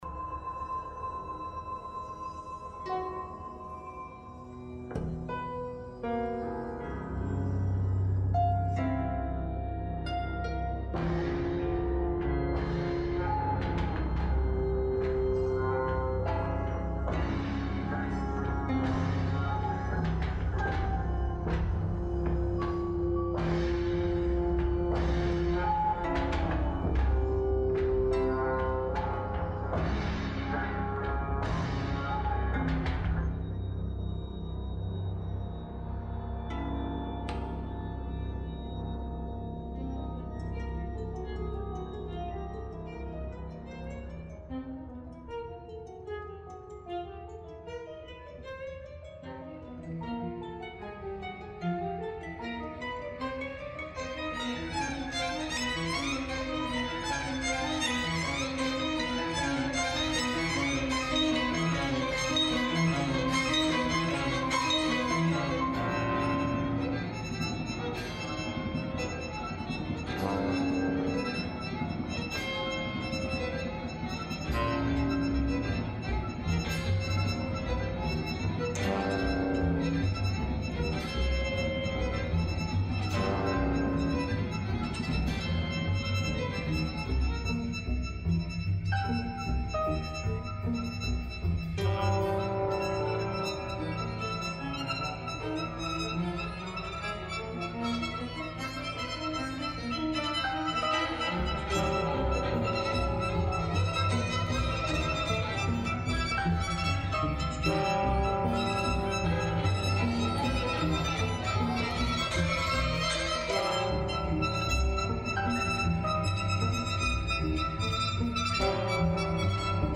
für 18 instruments
(all instruments plugged)